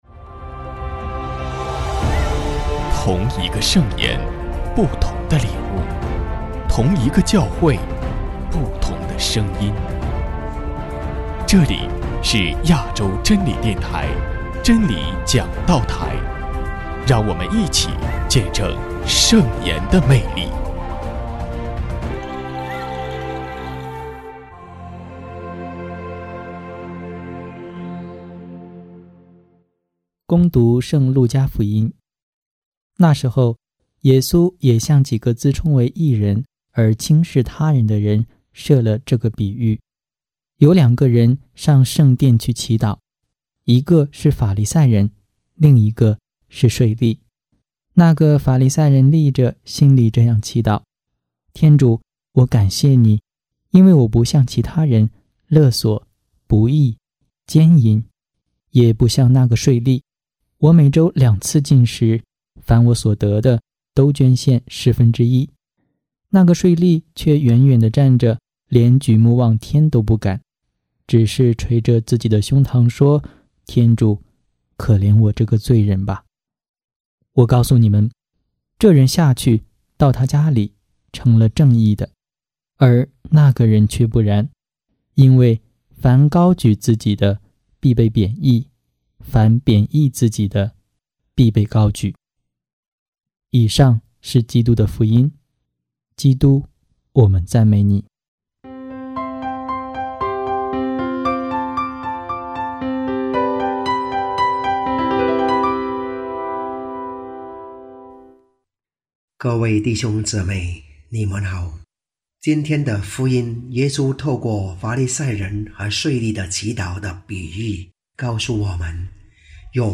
【真理讲道台 】95|丙年常年期第三十主日证道